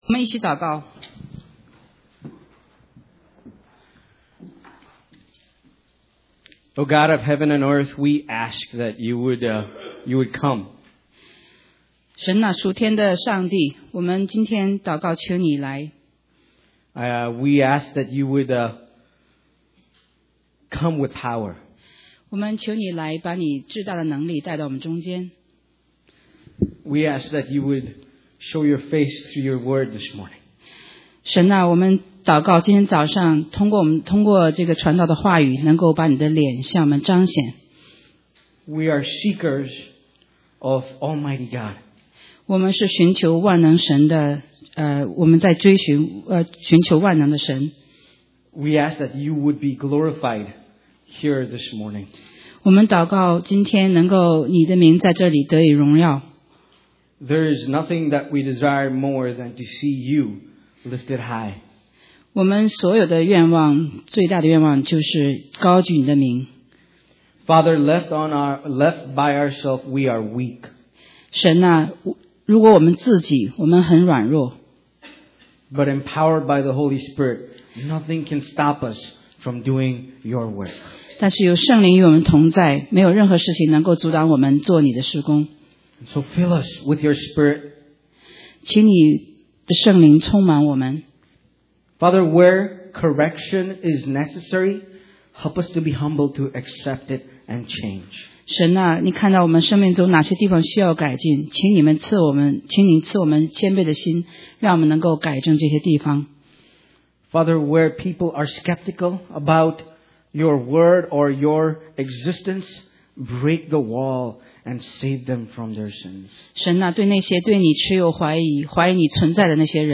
中文講道